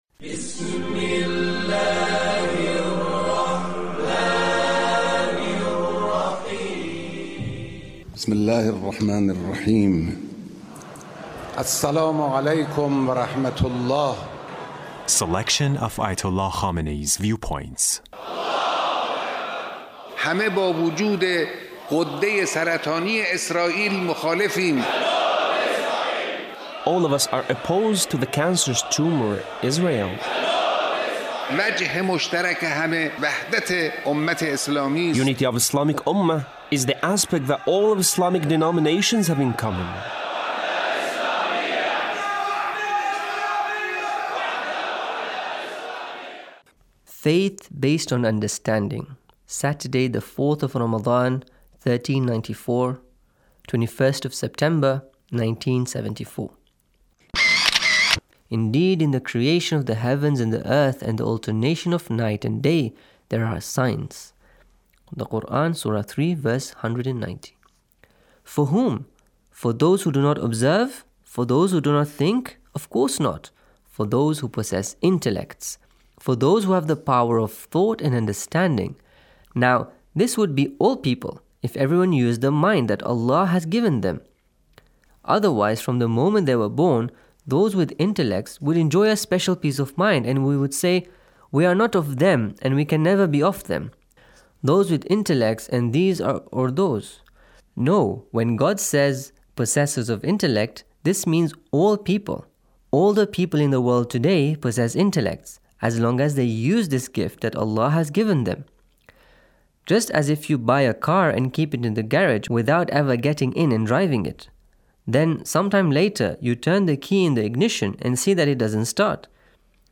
Leader's Speech (1553)